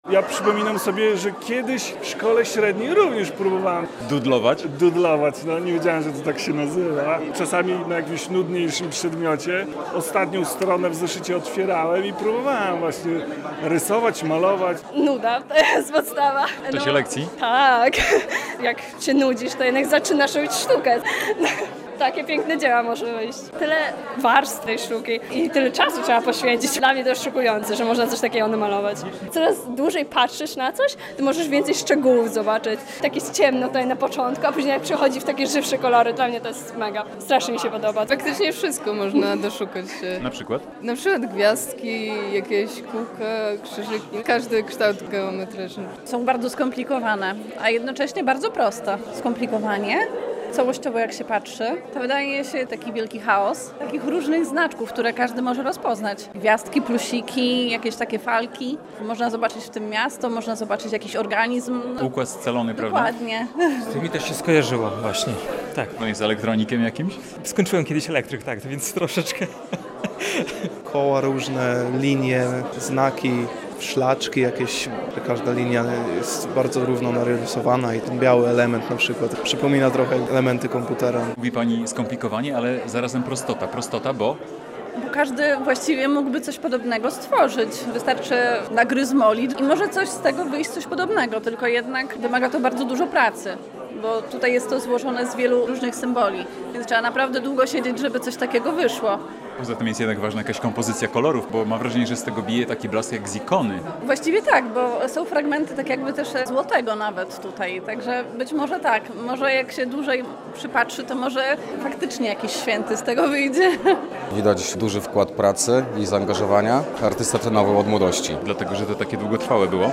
relacja
Ile da się wyczytać z tego nagromadzenia kresek, kółek, figur geometrycznych i kolorów? Sprawdzamy to na wernisażu, a dodajmy, że ta wystawa uświadomiła niektórym z nas, że też zdarza się nam doodlować.